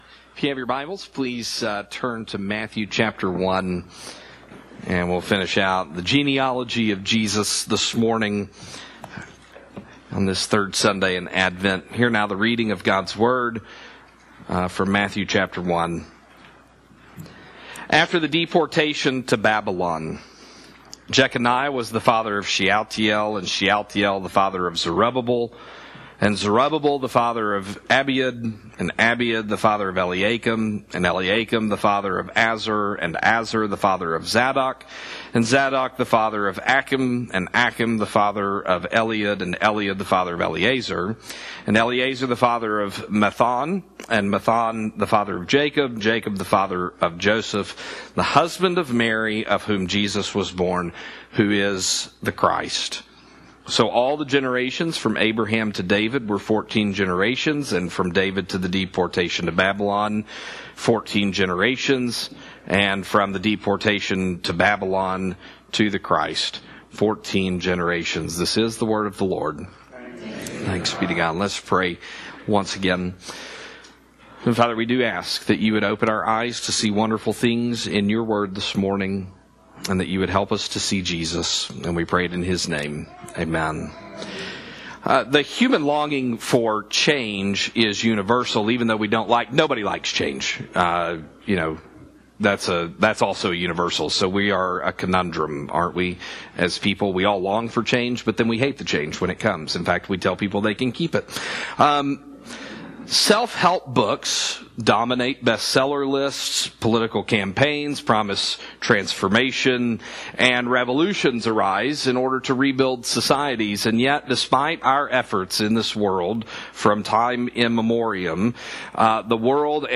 Sermons from Christ the King Presbyterian Church (PCA) in Austin, TX